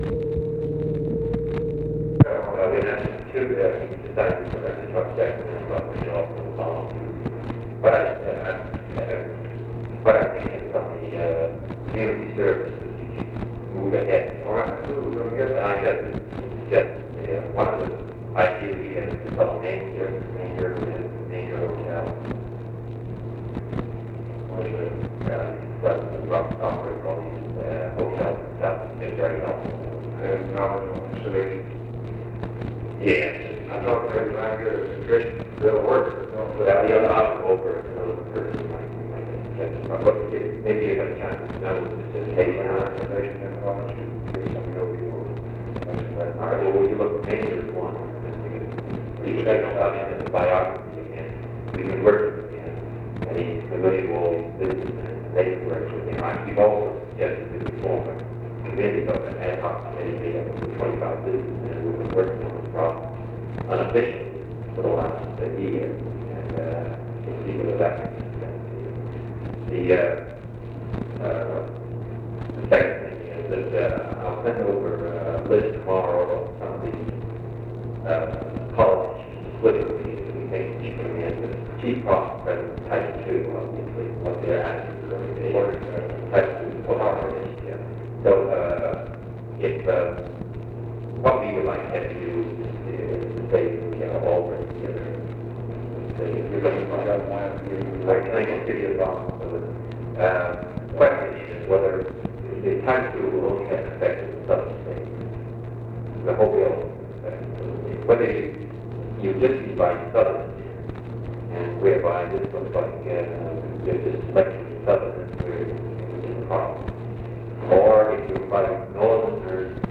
OFFICE CONVERSATION, June 12, 1964
Secret White House Tapes | Lyndon B. Johnson Presidency